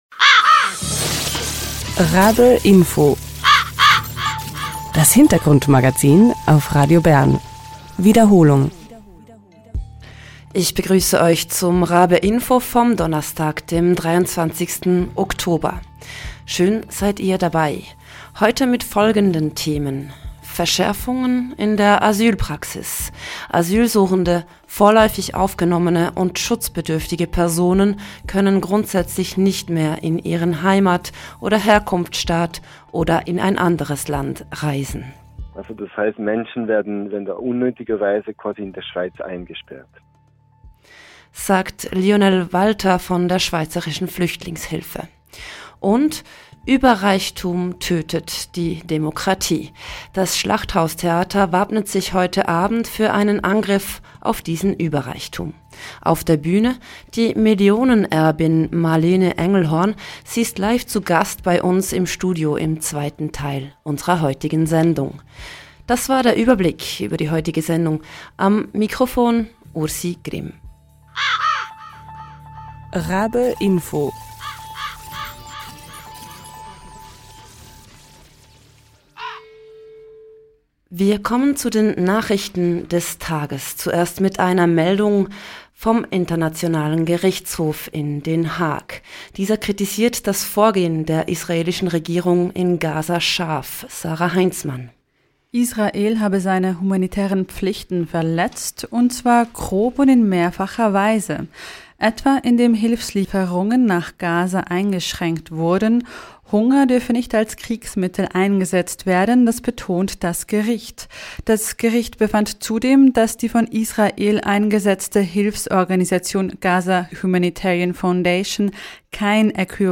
Die österreichische Millionenerbin Marlene Engelhorn wirkt beim Stück mit und besuchte uns im Studio für einen Talk.